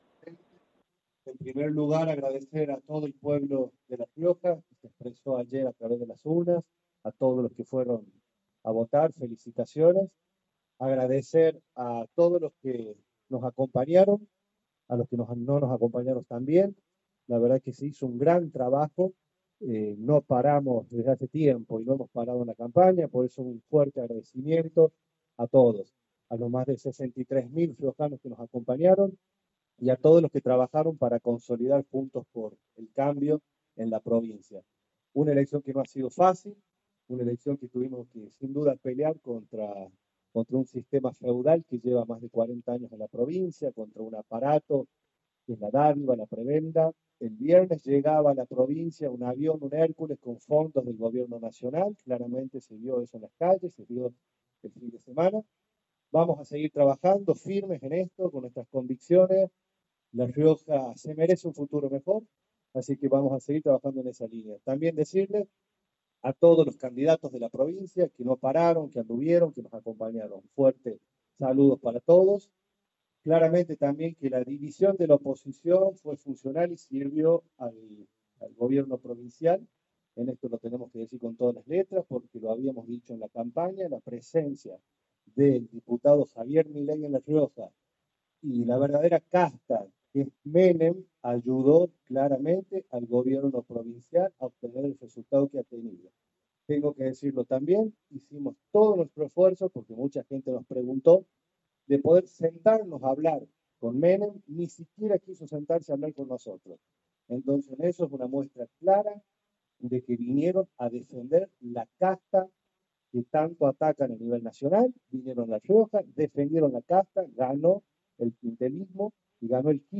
En conferencia de prensa y tras la derrota electoral